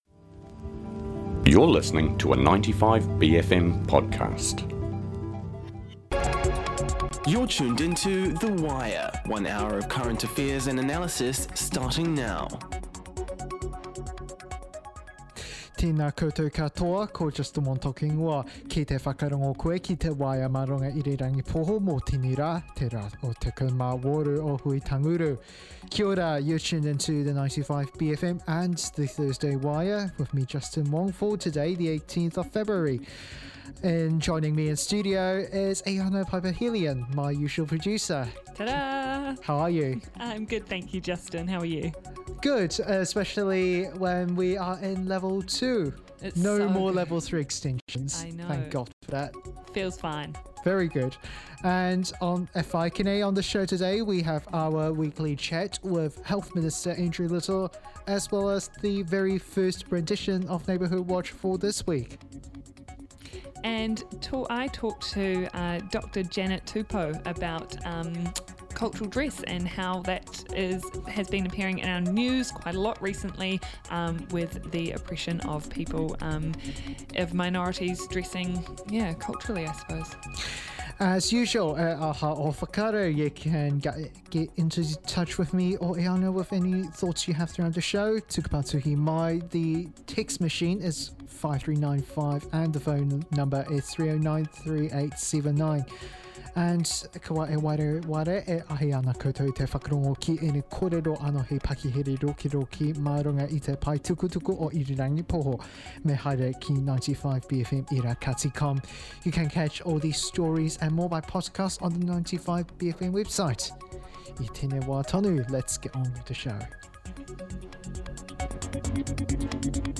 The Wire is 95bFM's long-running daily bastion of news, current affairs and views through the bFM lens.